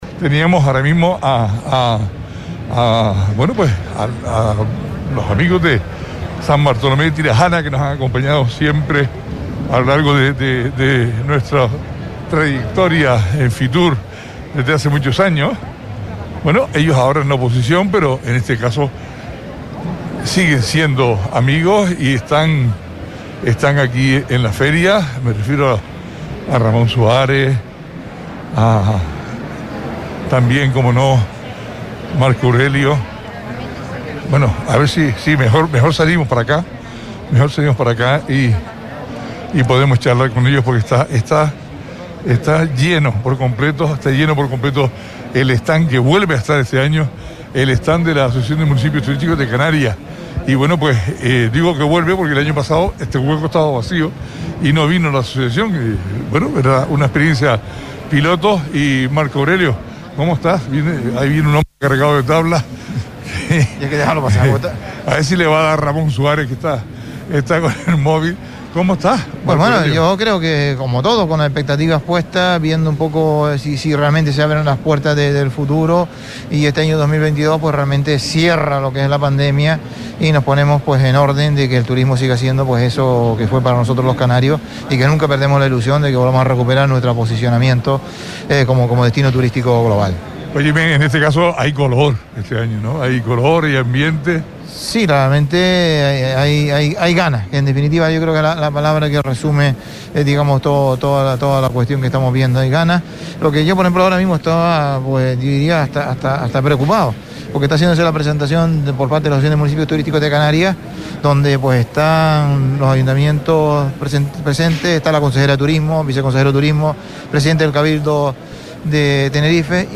Marco Aurelio Pérez exalcalde de San Bartolemé de Tirajana valoró en los micrófonos de Radio Sintonía Fuerteventura la primera jornada de fitur
Especial Fitur 22 | Entrevista a Marco Aurelio Pérez – 19.01.22